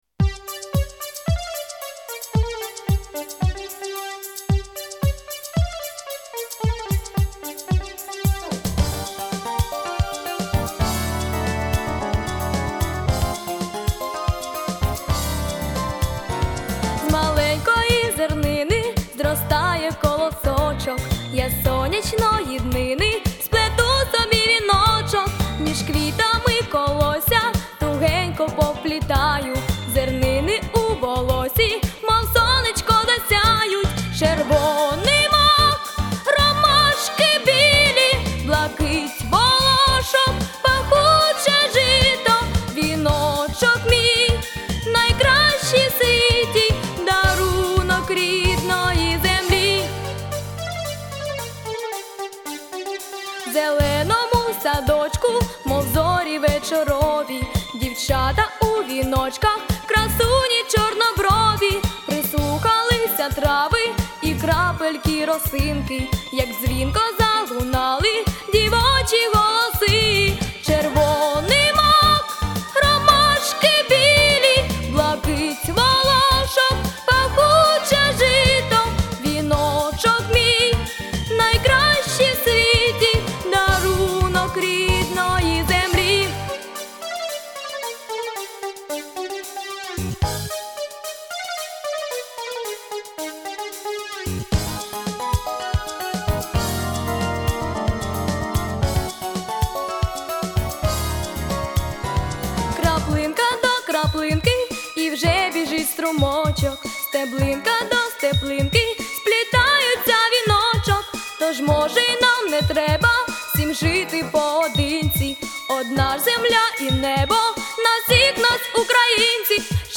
Помірна
Соло
Дитяча